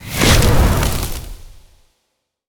nature_spell_vines_whoosh_02.wav